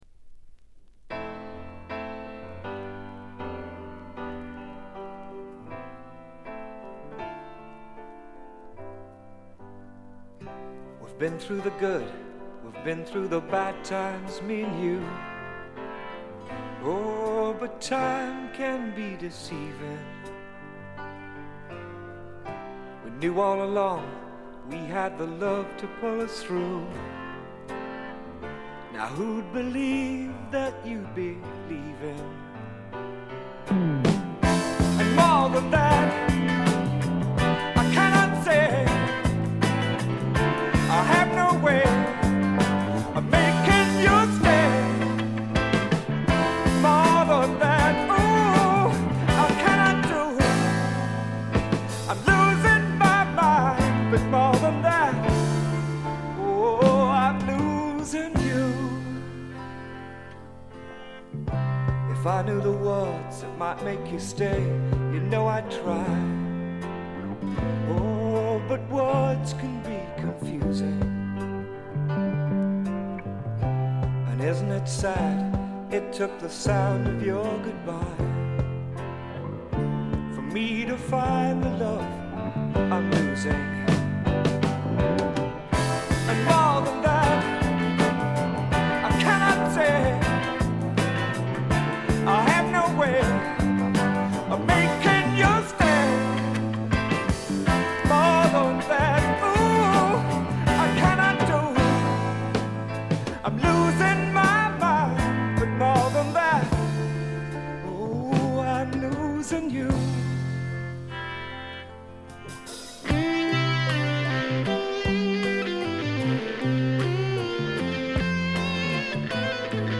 ほとんどノイズ感なし。
ピアノ系AOR系シンガー・ソングライターがお好きな方に大推薦です！
試聴曲は現品からの取り込み音源です。